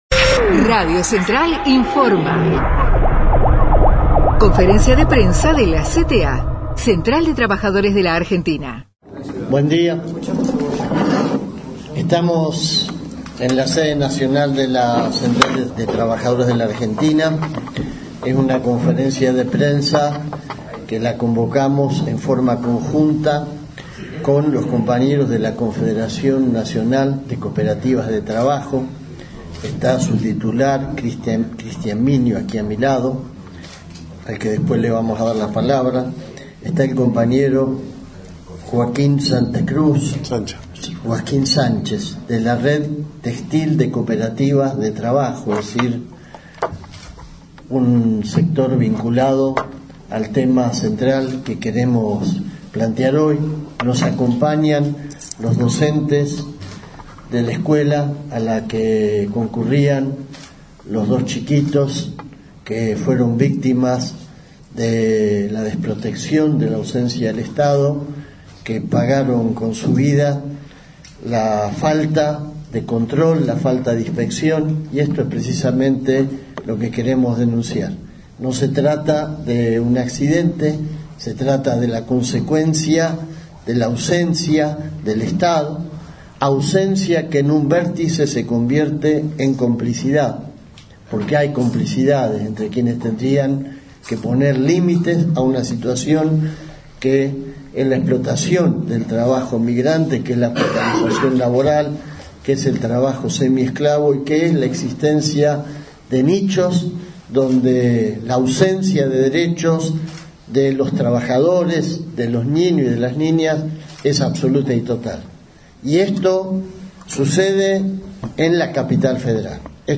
CONFERENCIA DE PRENSA DE LA CTA (abril-29)
final_conferencia_completa.mp3